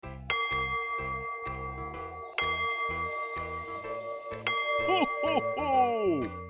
cuckoo-clock-03.wav